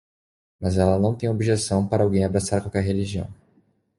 Pronounced as (IPA) /o.bi.ʒeˈsɐ̃w̃/